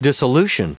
Prononciation du mot dissolution en anglais (fichier audio)
Prononciation du mot : dissolution